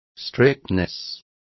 Complete with pronunciation of the translation of strictness.